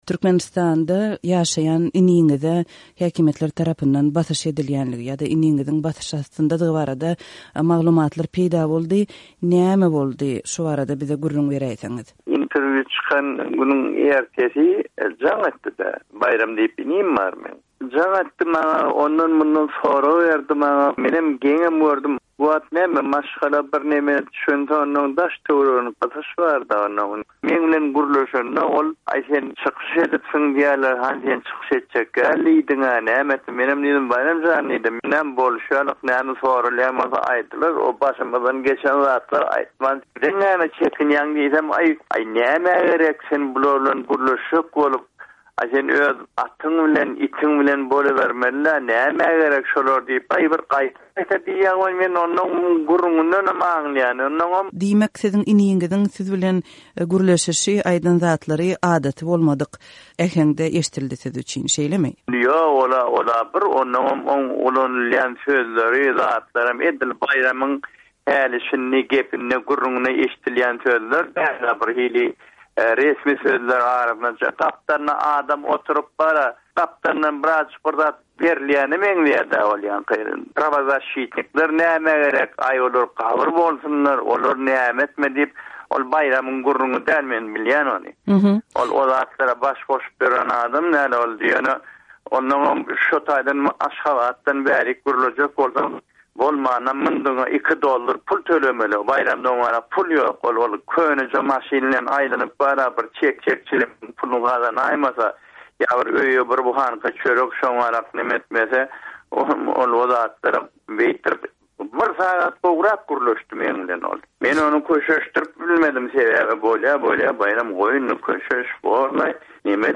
söhbetdeşligini diňläliň.